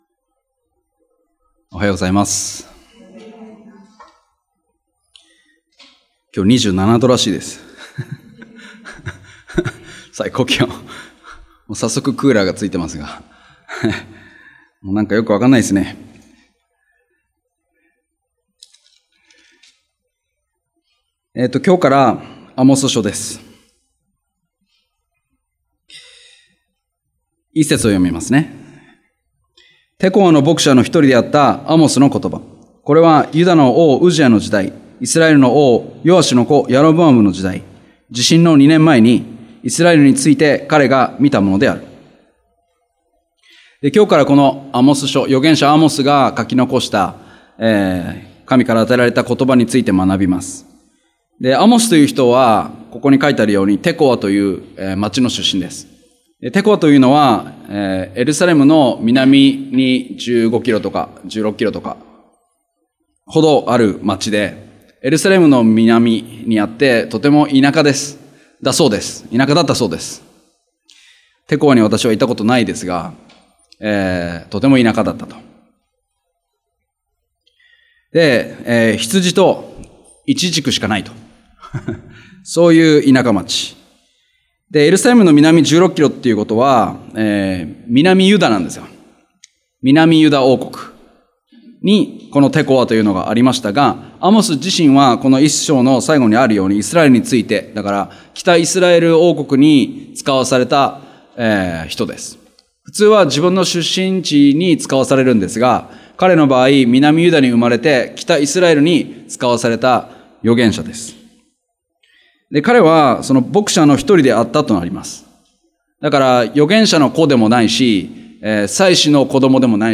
日曜礼拝：アモス書
礼拝やバイブル・スタディ等でのメッセージを聞くことができます。